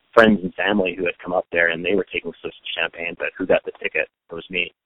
CUT-SCOTT-JUREK-SAYS-FRIENDS-AND-FAMILY-WERE-DRINKING-CHAMPAIGN-BUT-WHO-GOT-THE-TICKET-IT-WAS-ME.mp3